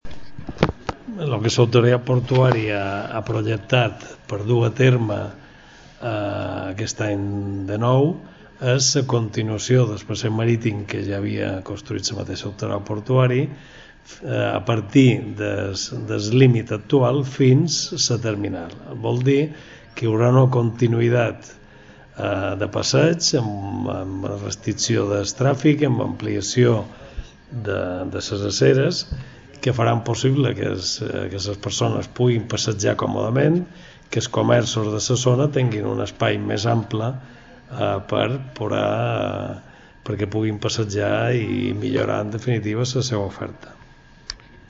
Declaraciones del presidente de la APB, Joan Gual de Torrella.
Declaracions Joan Gual. Reforma Passeig Marítim Alcúdia.MP3